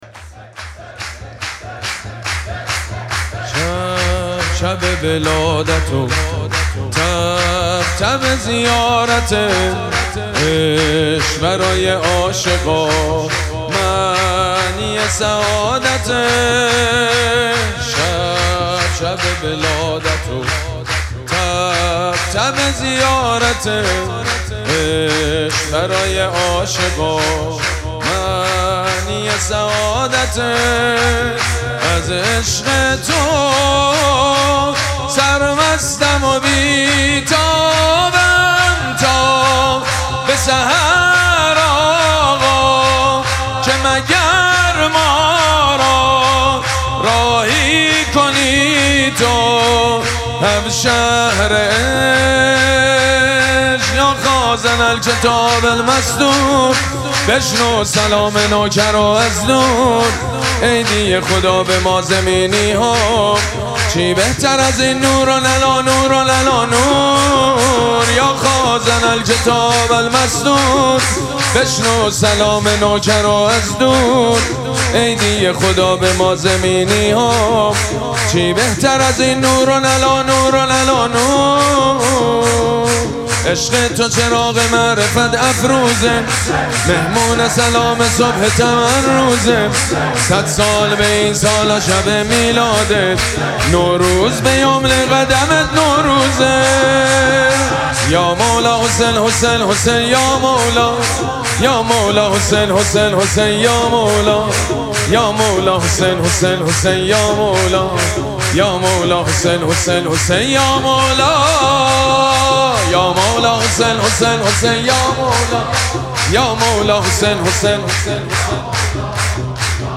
شب دوم مراسم جشن ولادت سرداران کربلا
حسینیه ریحانه الحسین سلام الله علیها
سرود
مداح
حاج سید مجید بنی فاطمه